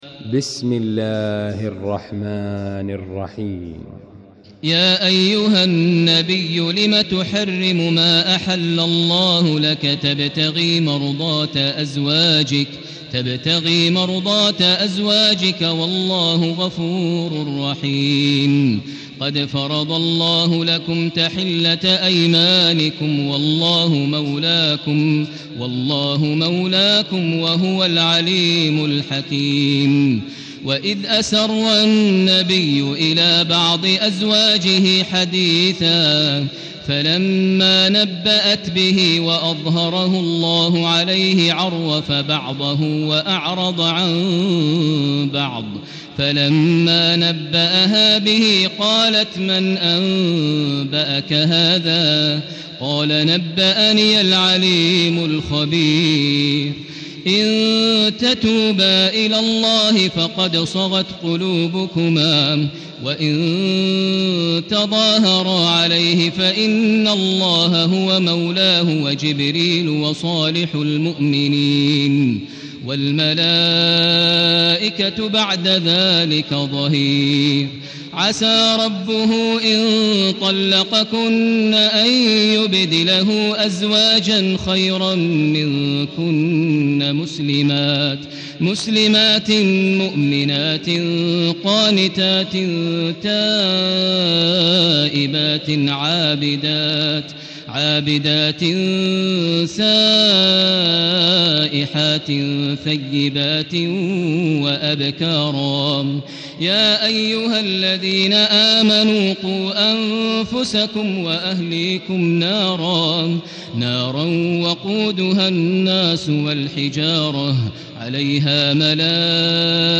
المكان: المسجد الحرام الشيخ: فضيلة الشيخ ماهر المعيقلي فضيلة الشيخ ماهر المعيقلي التحريم